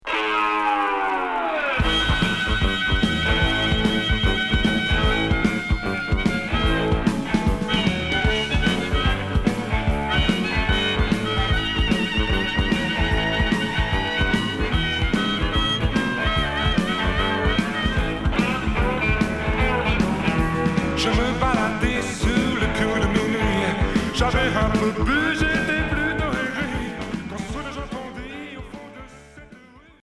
Rock hard